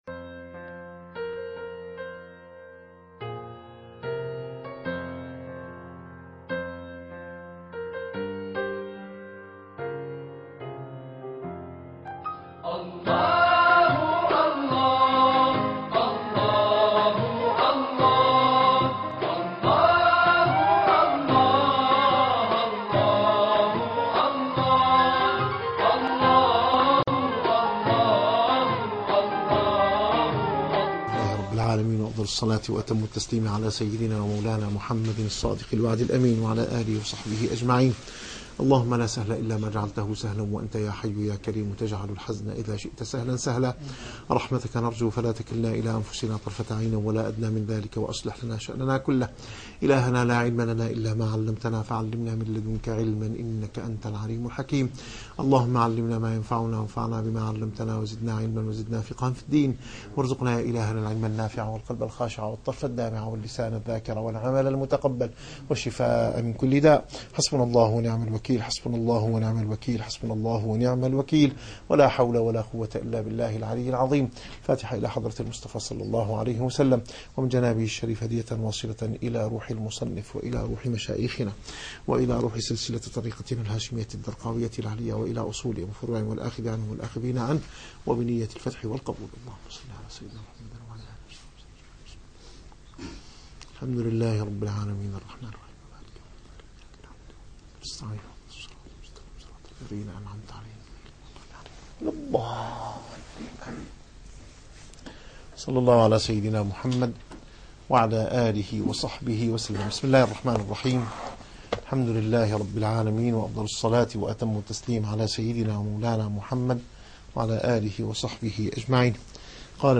الرسالة القشيرية - - الدروس العلمية - علم السلوك والتزكية - الرسالة القشيرية / الدرس الثاني والخمسون.